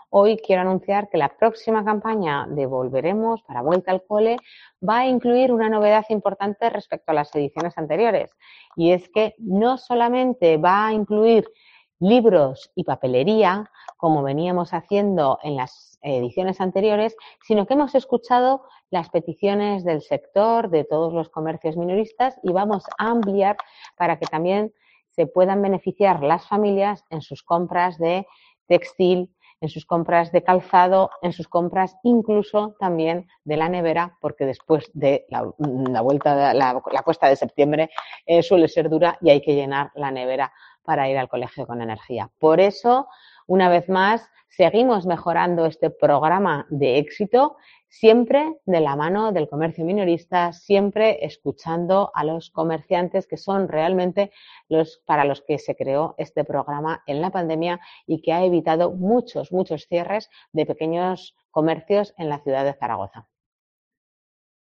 La alcaldesa de Zaragoza, Natalia Chueca, anuncia las novedades de 'Volveremos' en septiembre